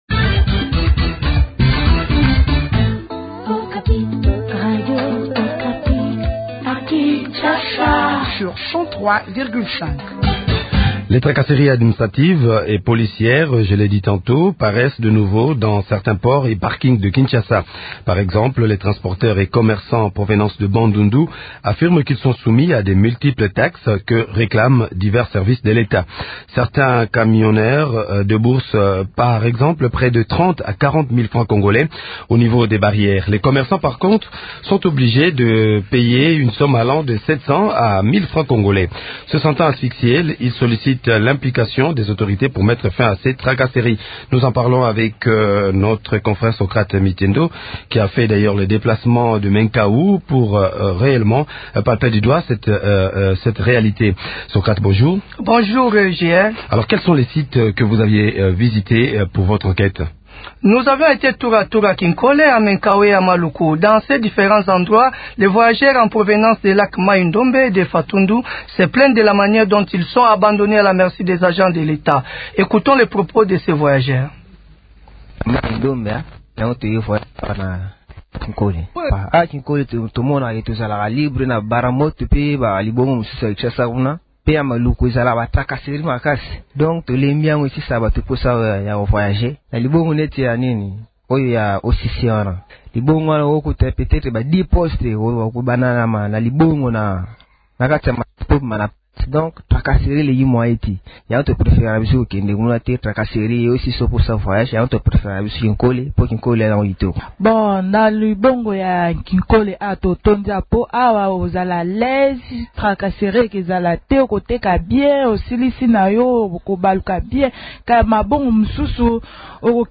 Les Tracasseries commencent à refaire surface dans les ports et les barrières érigées tout au long des routes, empêchant les congolais de circuler librement à l’intérieur du territoire national. Nous avons fait le tour des ports de Baramoto, Occ, Kinkole, MAluku, et MEnkao pour se rendre compte de la réalité sur le terrain .